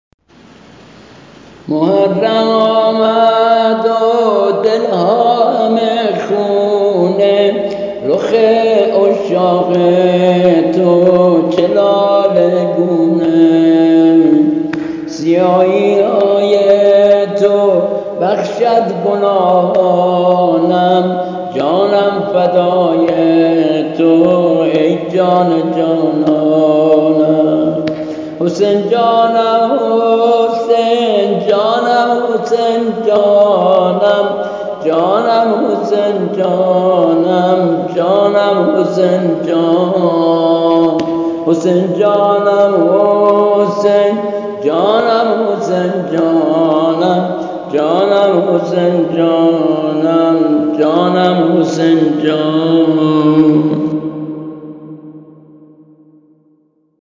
◾زمزمه و شور سینه زنی